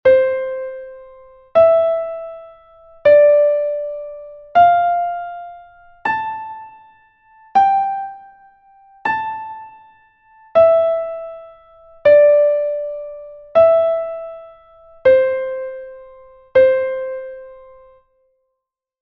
note recognition exercise 3